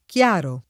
kL#ro] agg., s. m., avv. — come s. m., tronc., raro, solo nella locuz. chiar di luna (com. chiaro di luna): un’eccitazione stranita come di lepri al chiar di luna [